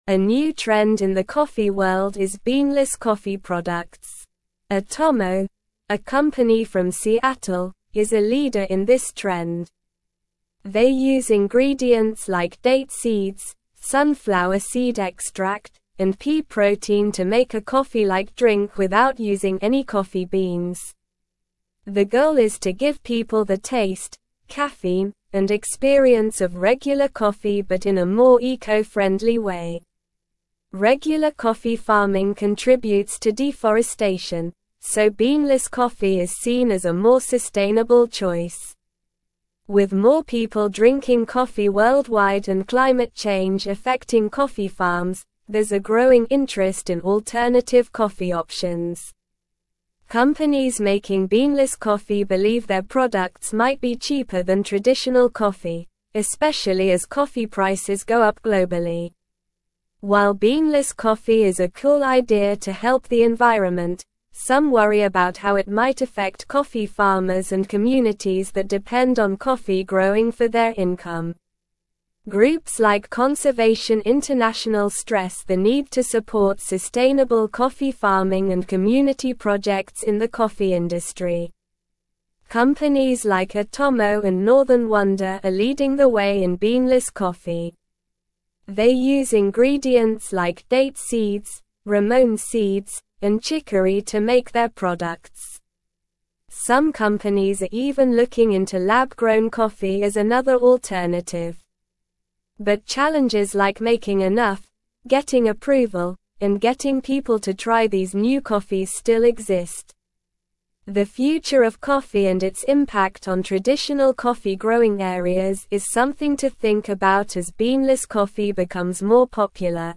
Slow
English-Newsroom-Upper-Intermediate-SLOW-Reading-Emerging-Trend-Beanless-Coffee-Revolutionizing-the-Industry.mp3